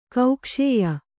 ♪ kaukṣēya